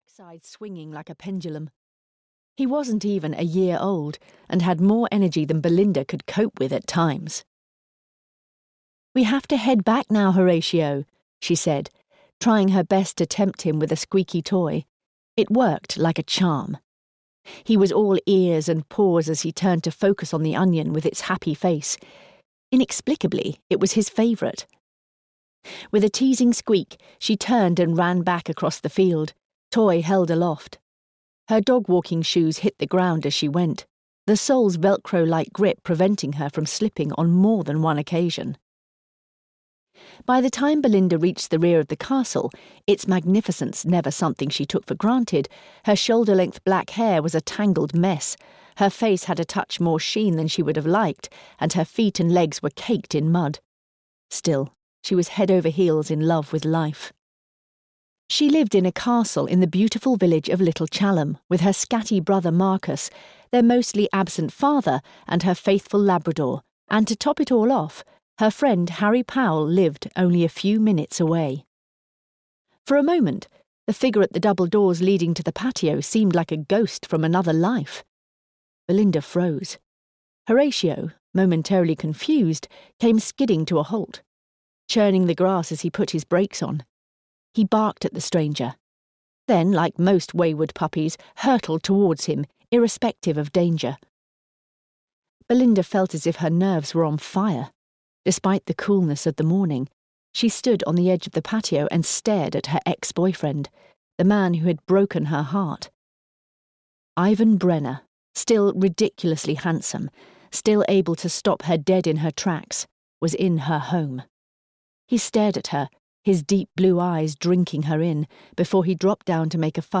Audio knihaMurder at the Gardens (EN)
Ukázka z knihy